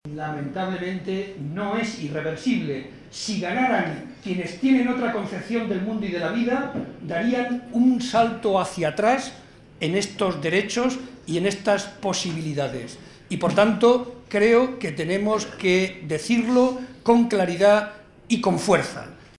Estas palabras fueron pronunciadas por Barreda en el tradicional vino de Navidad organizado por la Agrupación Socialista de Ciudad Real, momento en el que aprovechó para reivindicar el papel fundamental de la educación para lograr los valores anteriormente mencionados.
Vino navideño PSOE Ciudad Real